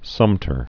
(sŭmtər), Fort